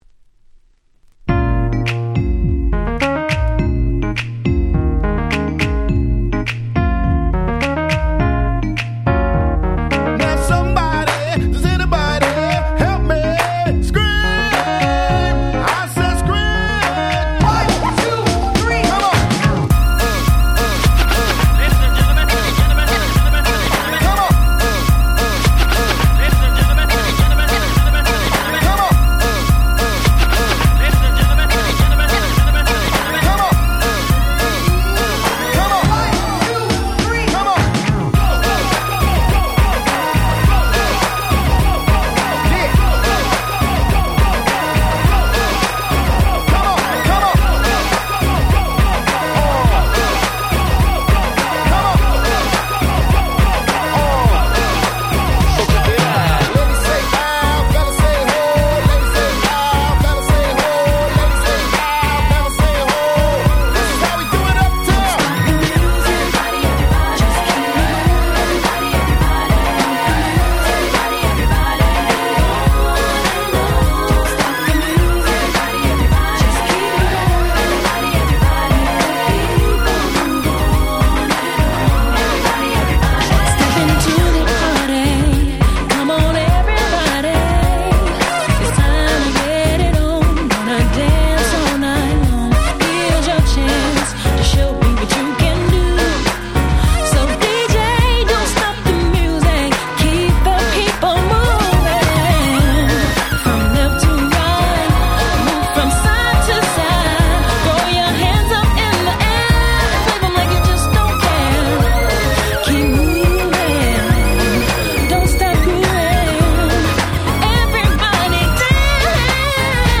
90's R&B デリシャスミックス 勝手にリミックス ミックス物